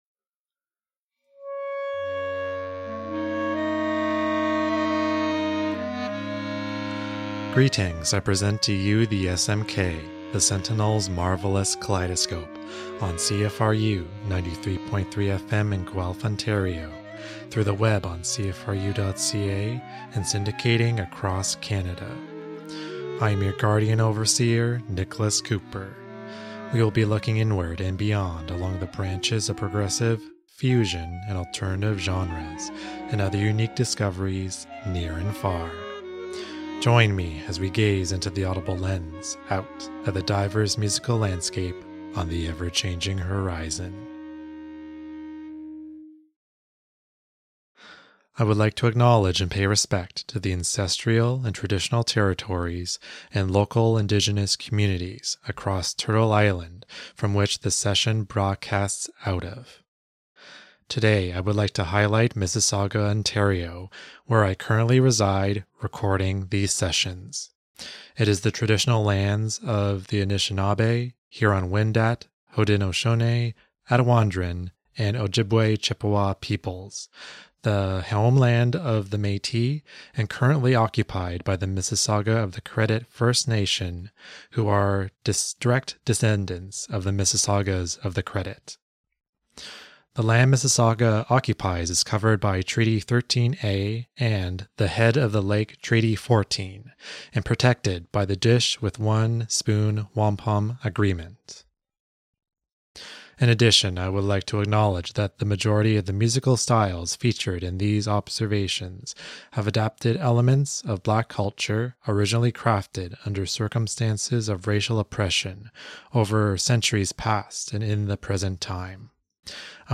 Looking inward and beyond at progressive, fusion and alternative sonic styles, near and far...